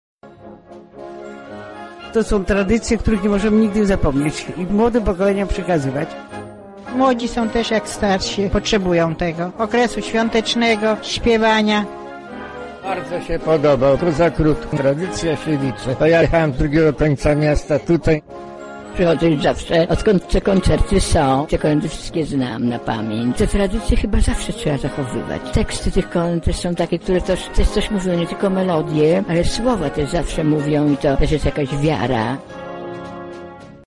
Tak śpiewał chór Roztoczańskiej Straży Ochrony Przyrody podczas wspólnego kolędowania.
Słuchacze opowiedzieli o swoich wrażeniach.
Kolędowanie
Kolędowanie.mp3